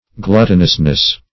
gluttonousness.mp3